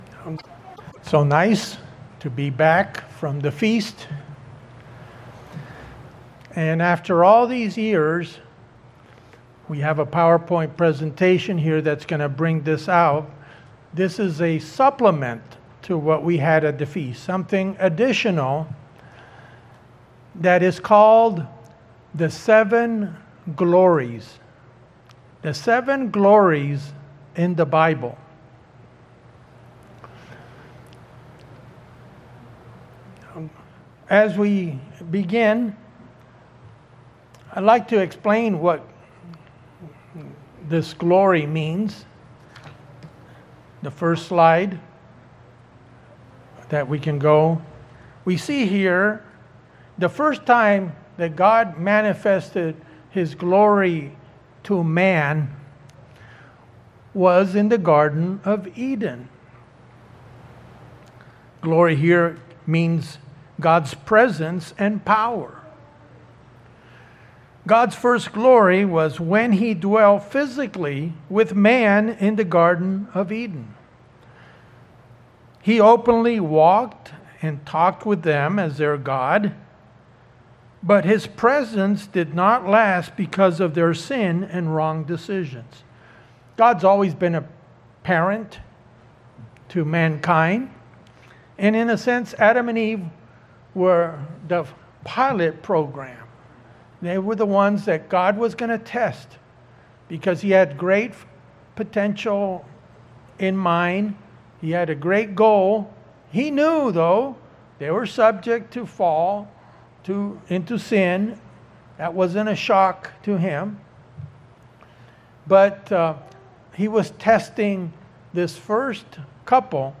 In this PowerPoint sermon, we hear about the seven glories of the Lord. From the very beginning and into the future, God's seven appearances to mankind come in various forms.